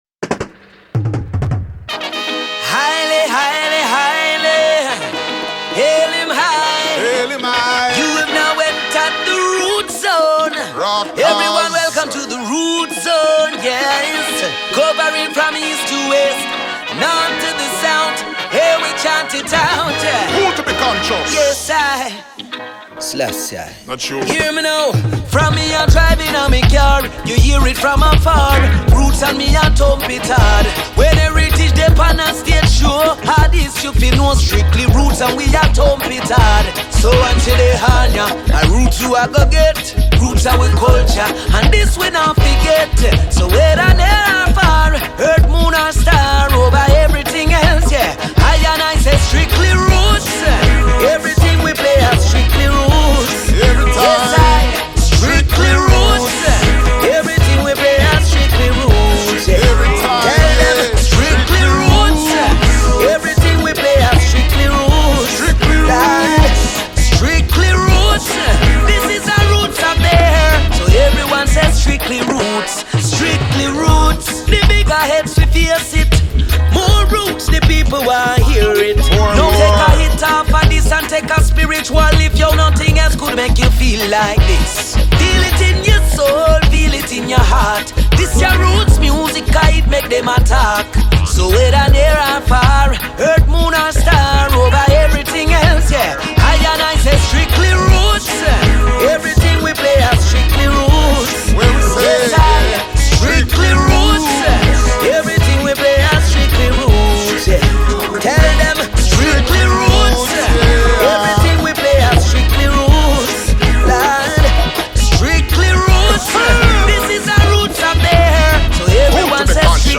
groupe de reggae